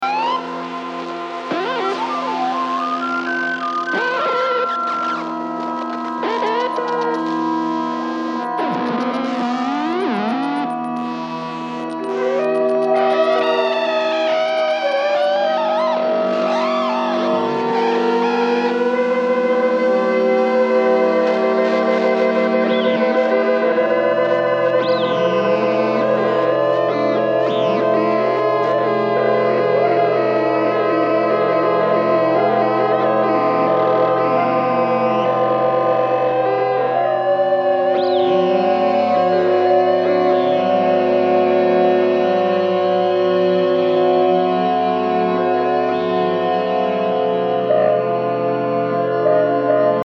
Experimental >
Ambient, Drone >